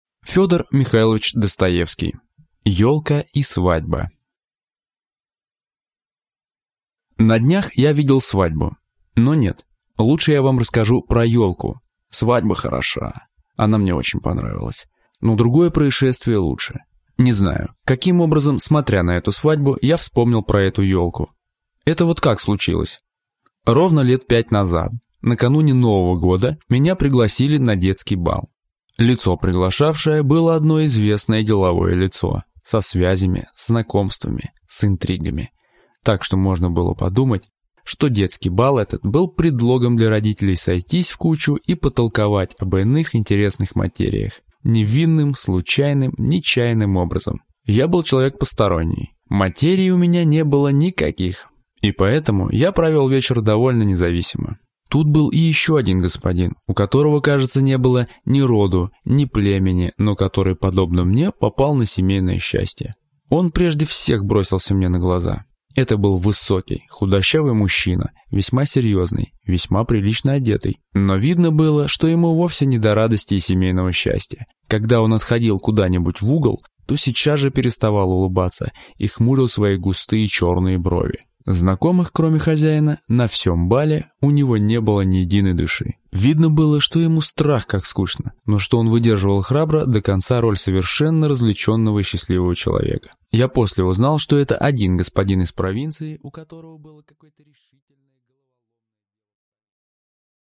Аудиокнига Елка и свадьба | Библиотека аудиокниг